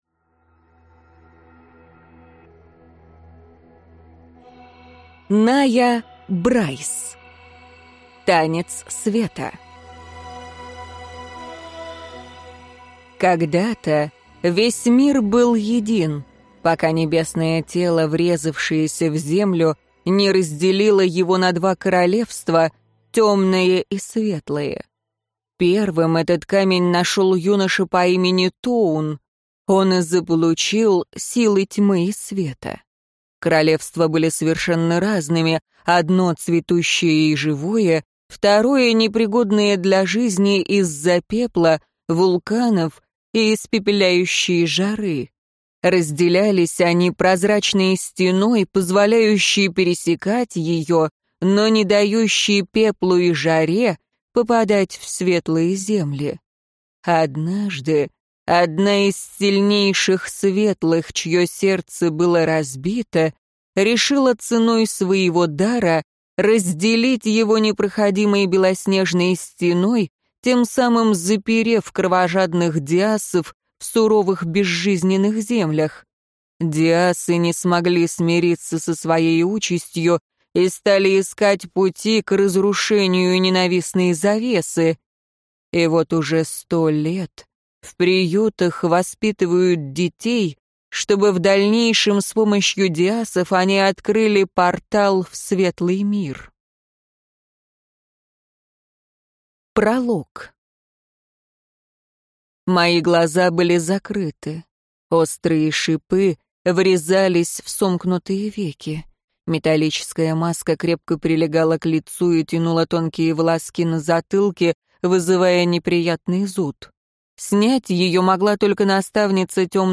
Аудиокнига Танец света | Библиотека аудиокниг